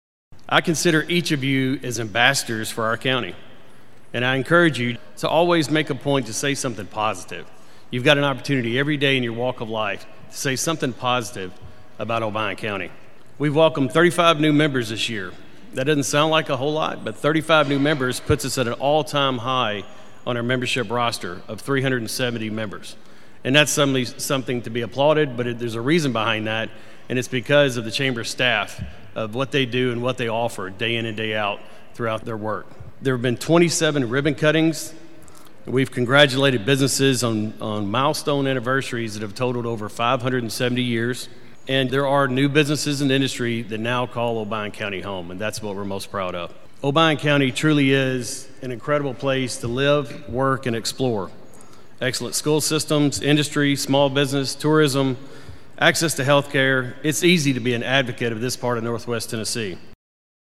The Obion County Joint Economic Development Council and Chamber of Commerce held their annual banquet last week.
The banquet was held in front of a sold out crowd of 300 people at Discovery Park of America.